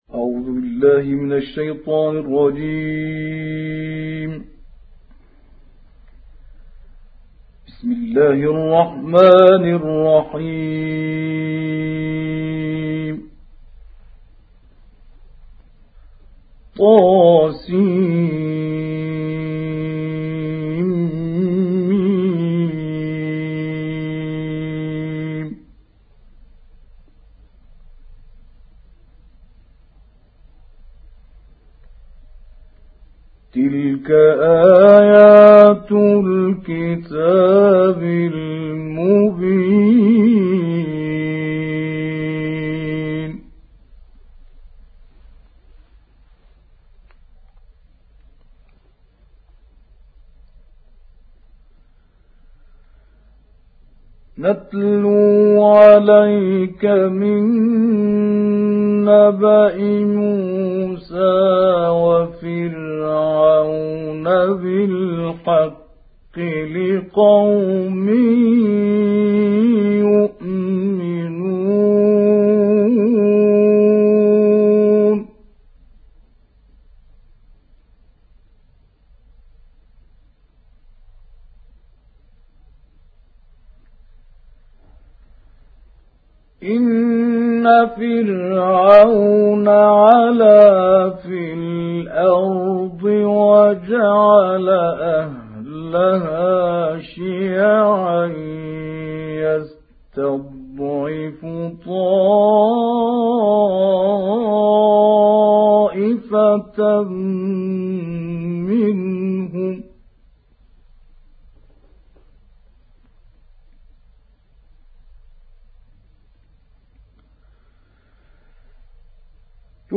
تلاوت استودیویی